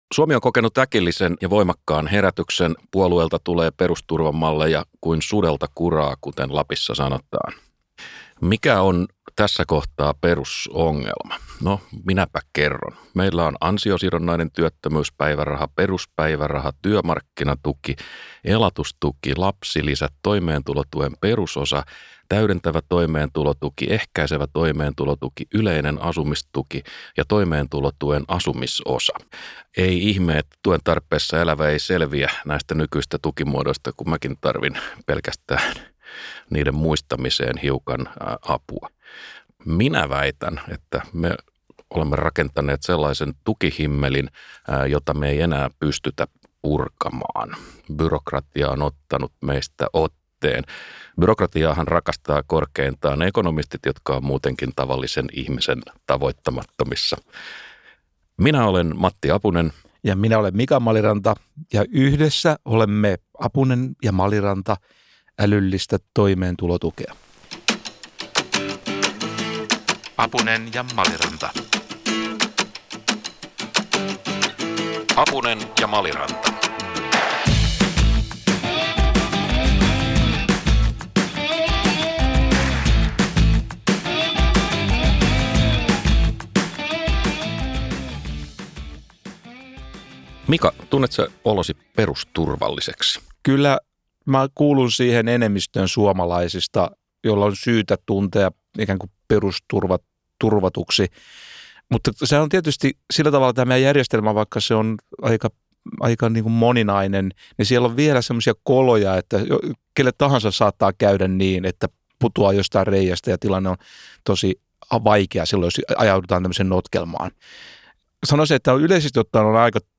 Käsittelyyn pääsevät niin brittiläinen Universal Credit, suomalainen perusturva kuin tuore aktiivimallikin. Lopuksi keskustelijakaksikko niputtaa yhteen ajatukset paremman perusturvan osasista.